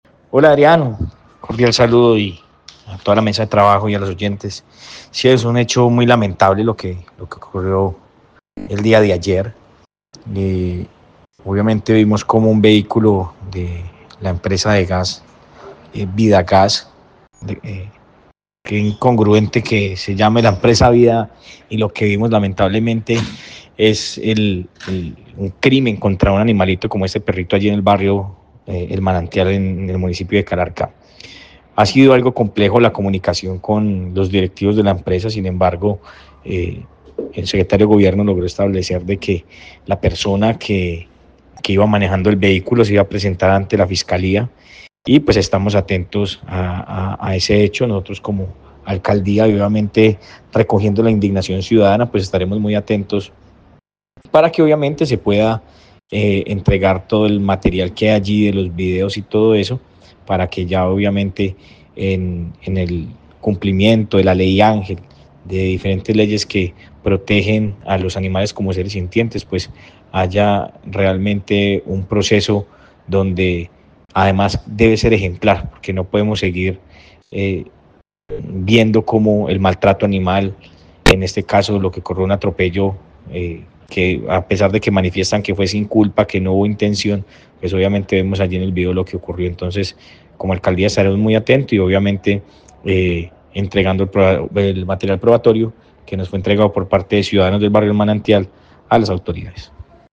Sebastián Ramos, alcalde de Calarcá